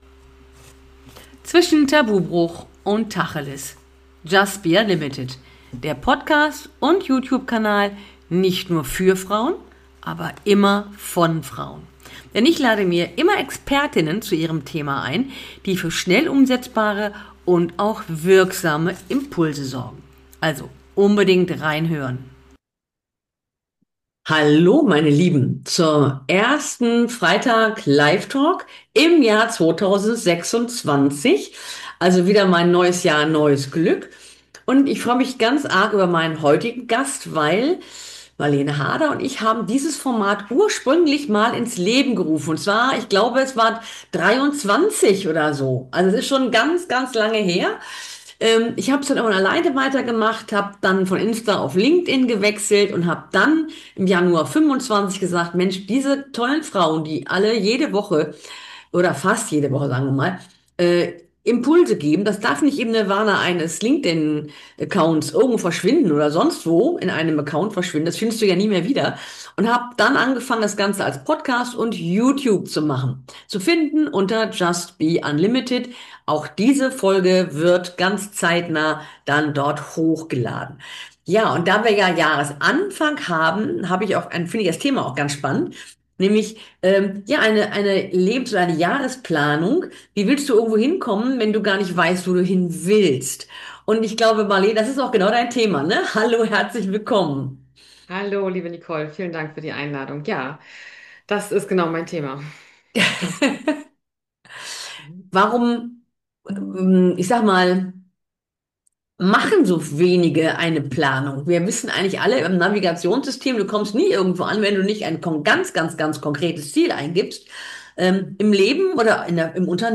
Live-Talk